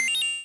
Upgrade_Buy.wav